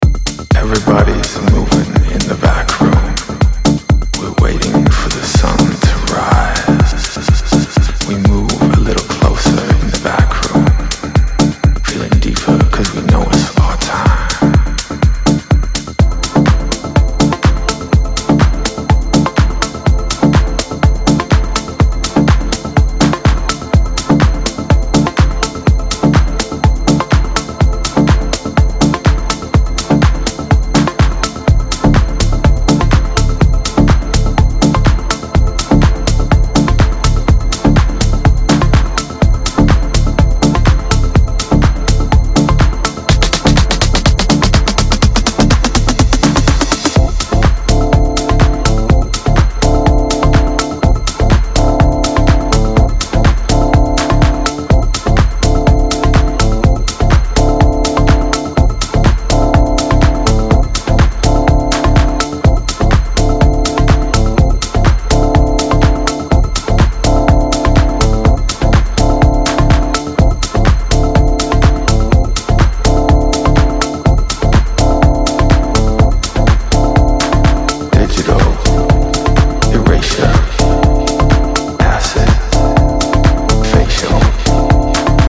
typically deep and soulful cuts.